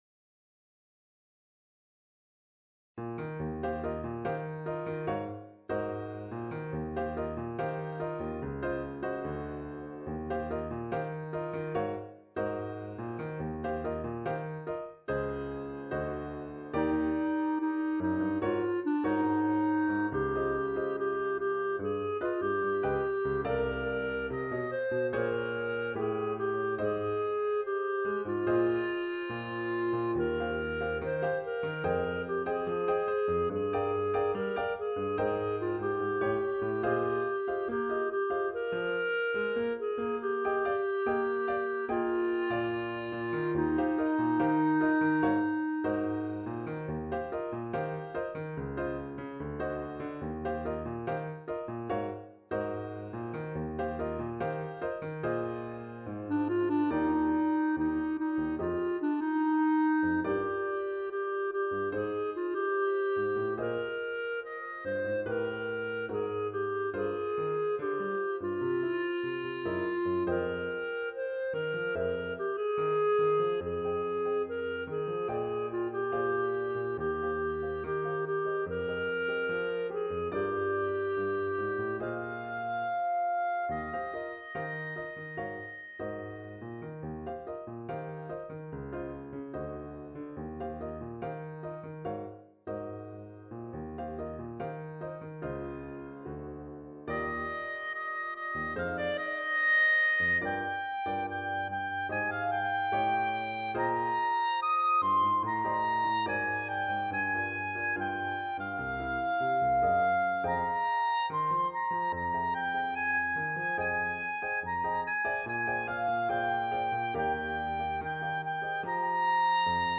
Clarinet and Piano